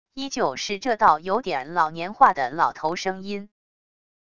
依旧是这道有点老年化的老头声音wav音频